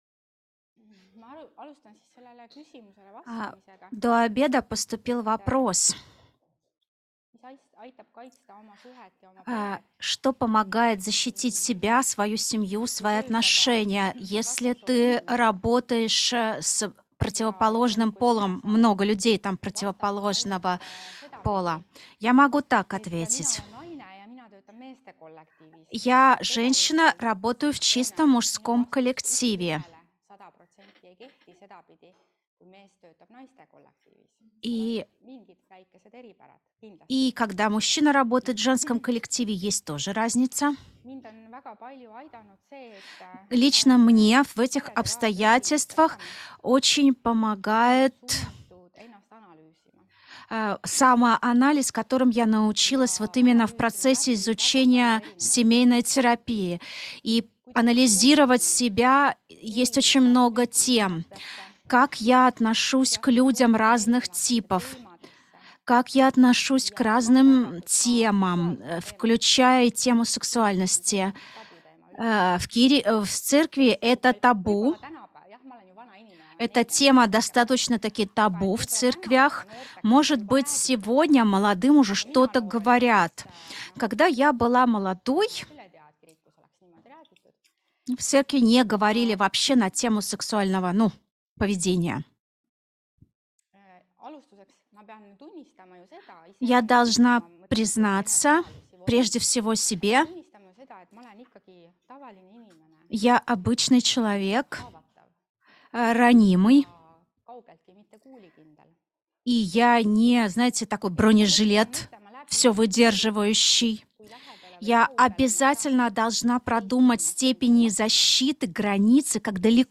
Семейное консультирование. 3. лекция [RU] – EMKTS õppevaramu